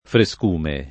[ fre S k 2 me ]